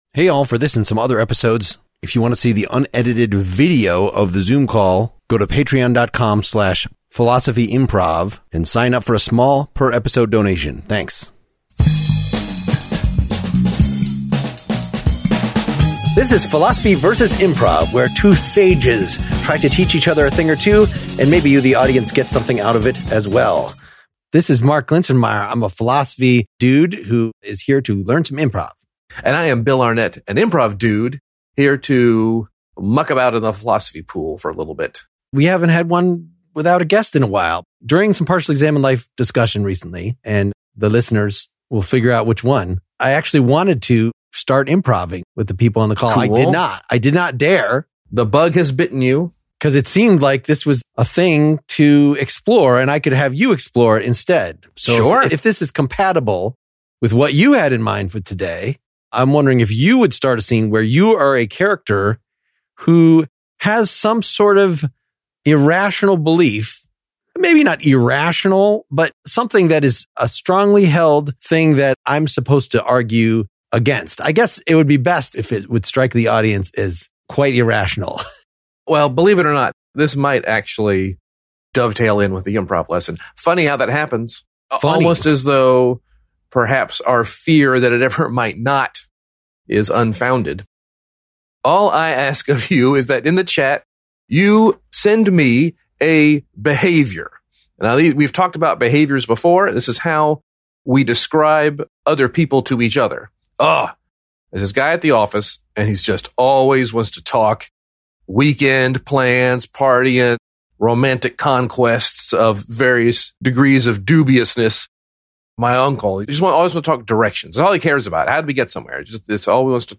act out a couple of scenes of a person trying to convince a stubborn person of something.